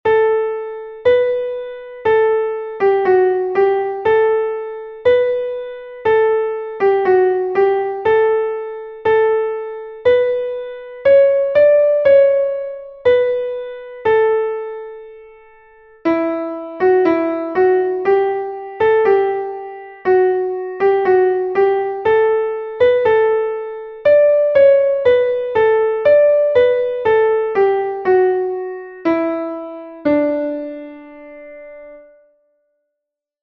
Einstimmige Melodie im Violinschlüssel, D-Dur, 4/4-Takt, mit der 1.
o-du-froehliche_klavier_melodiemeister.mp3